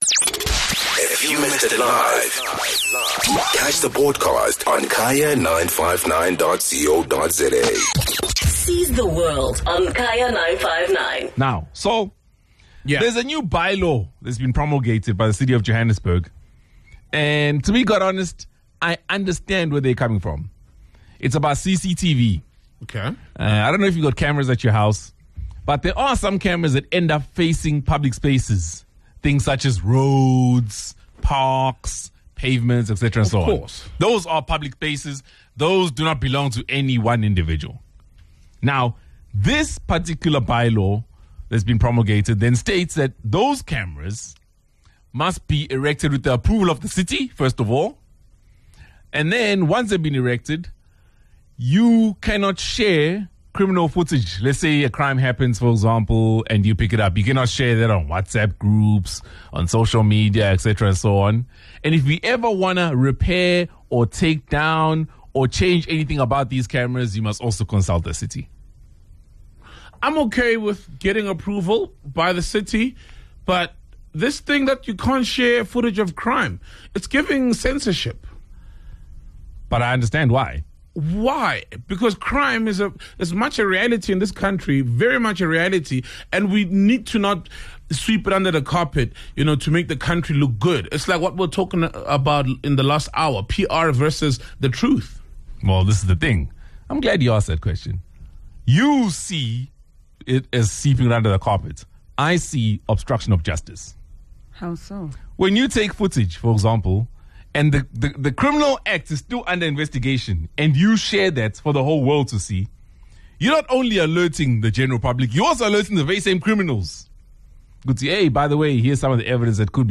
The team held a heated dialogue on this new bylaw and the consultation process before promulgating it.